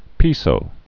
(pēsō)